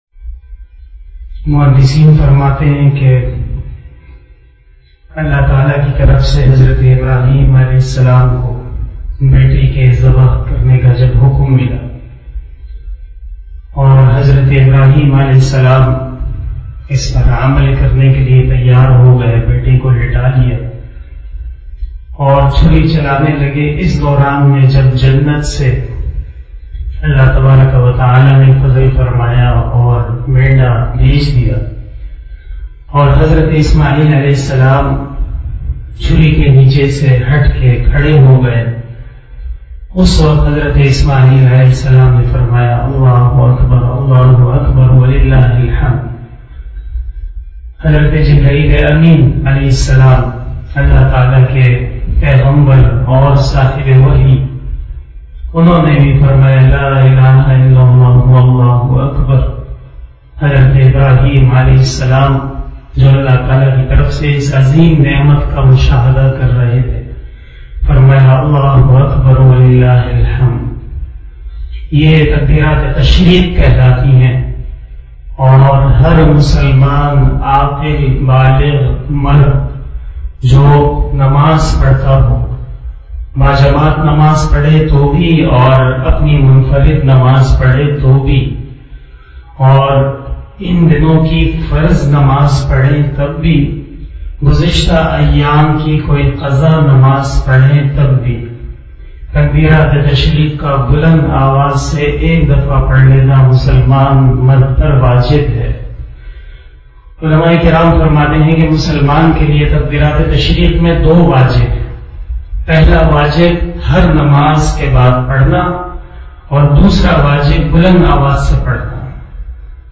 035 After Fajar Namaz Bayan 20 July 2021 (09 Zilhajjah 1442HJ) Tuesday